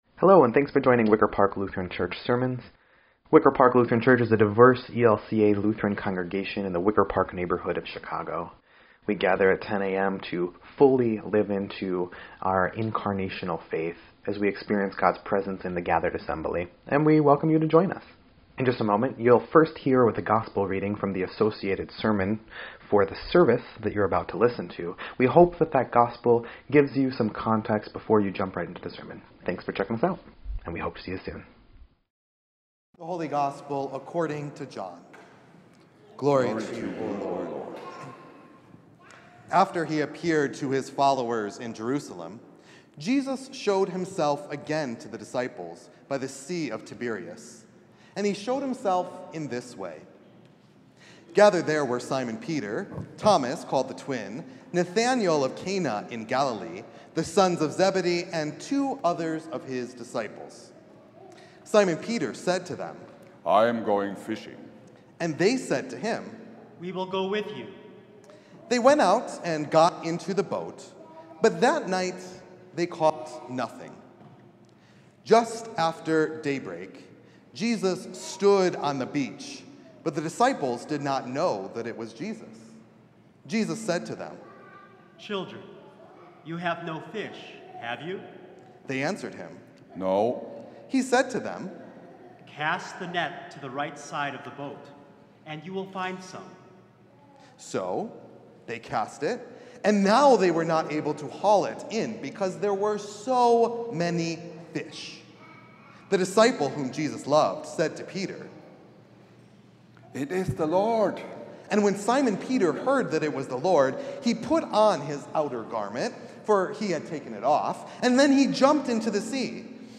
5.4.25-Sermon_EDIT.mp3